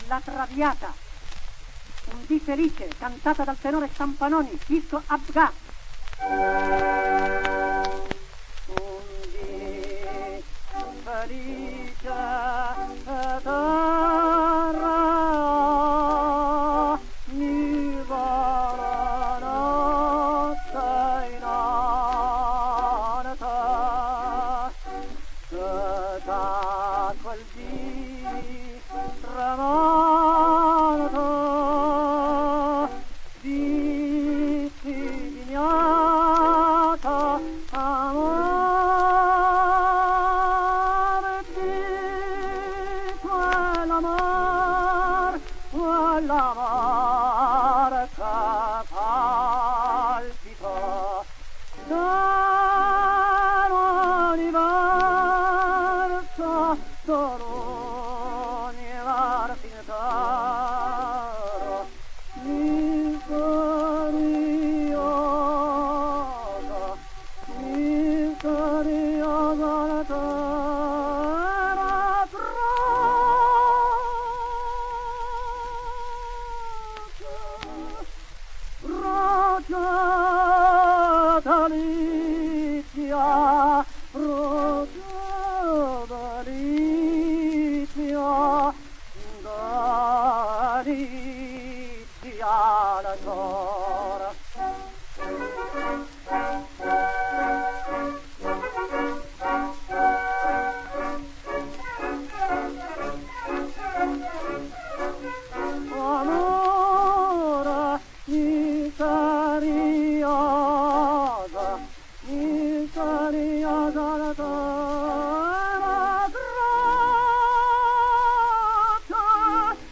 It's idiosyncratic at times: the way he rolls his "r" (he always announces himself) – for instance in "Carrrrrrrmen"; the way he sings "e non ho amato mai tanto la vita", which seems pretty much like "e non ho amado mai tanto la vida".